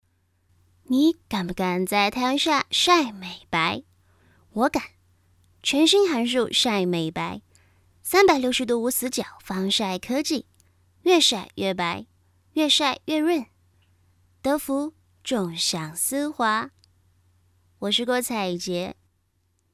标签： 自然
配音风格： 自然